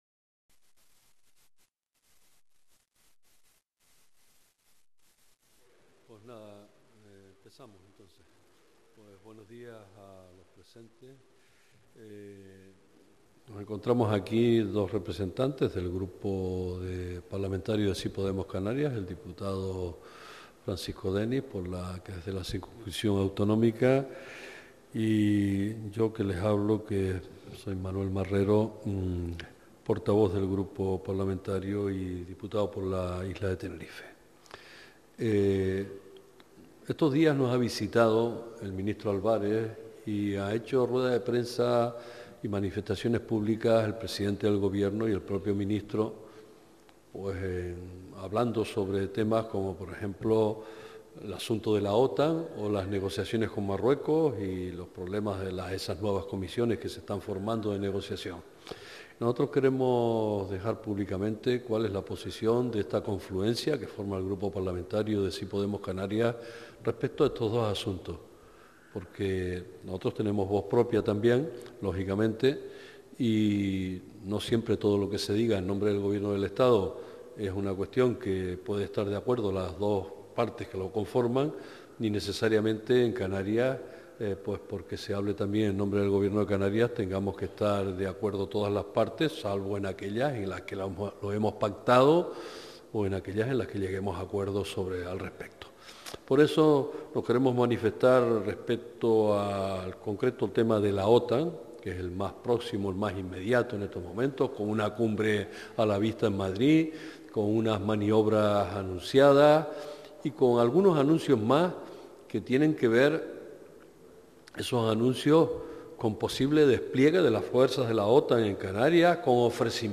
Rueda de prensa del GP Sí Podemos Canarias sobre análisis de diversos asuntos de actualidad - 12:00